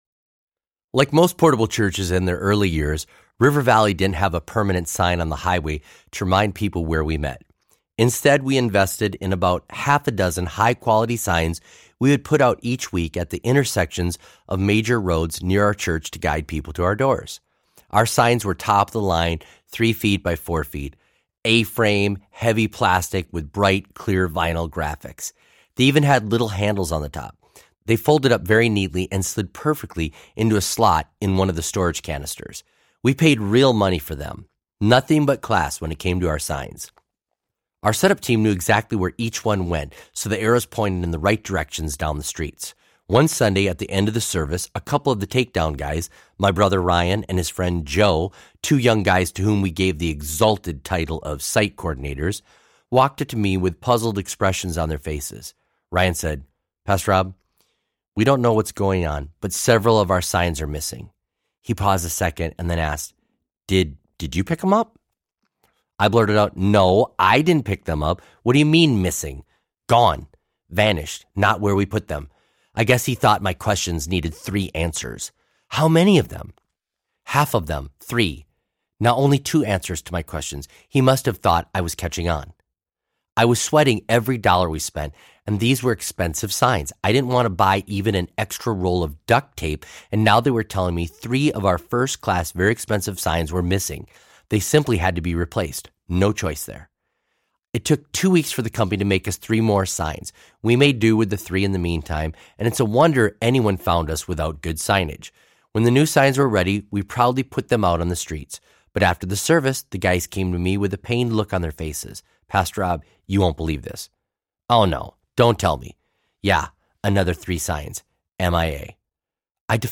Fix It! Audiobook
5.18 Hrs. – Unabridged